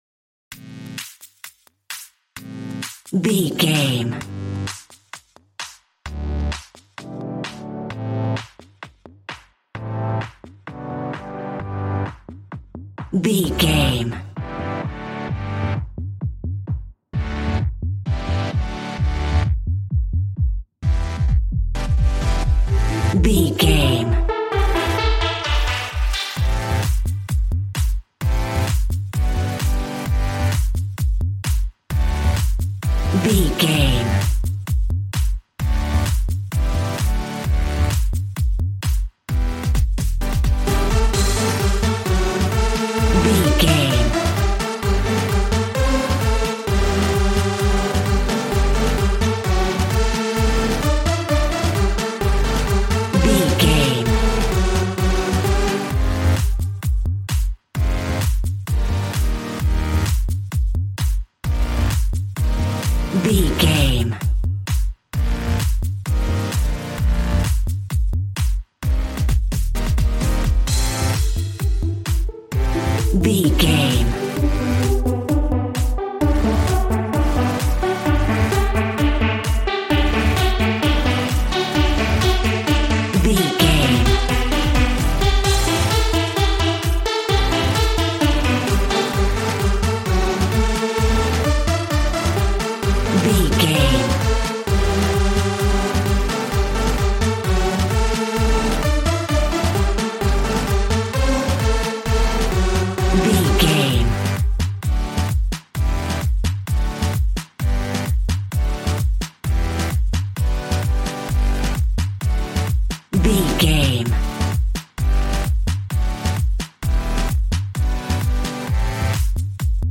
Ionian/Major
D
Fast
groovy
energetic
fun
synthesiser
drums